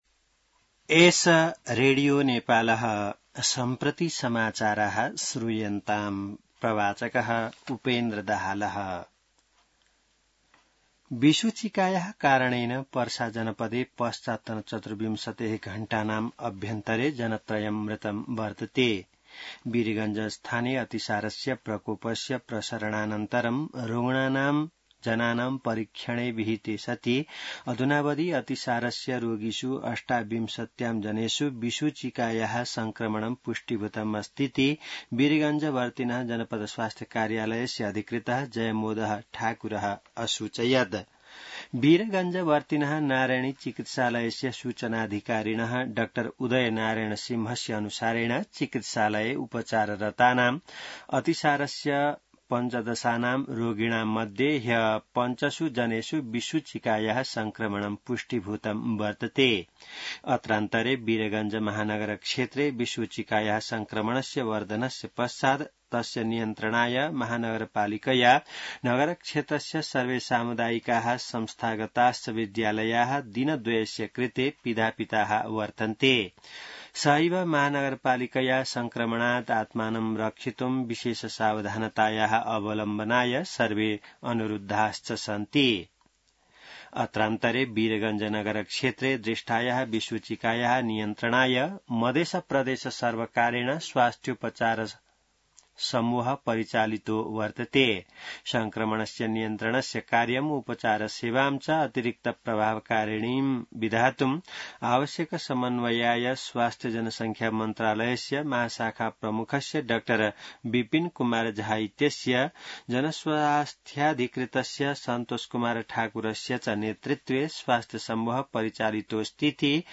संस्कृत समाचार : ८ भदौ , २०८२